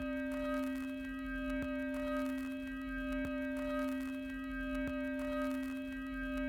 Vinyl_Tone_Layer_01.wav